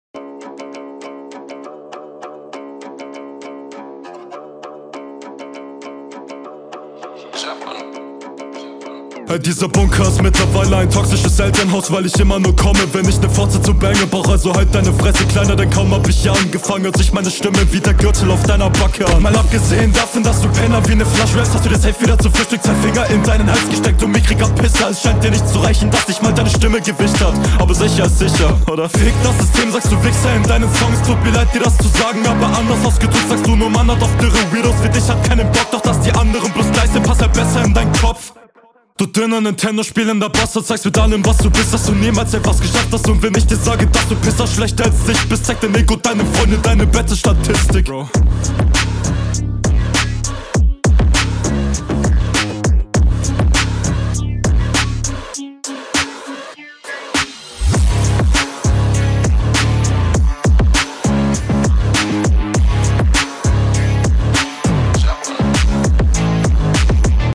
Stimmeinsatz wirkt bisschen krampfhaft gedrückt aber klingt stabil Flow an sich ok, aber wenn geshufflet …
Flow ist gut aber es gibt 1-2 Stellen die uncool sind.